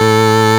54_24_organ-A.wav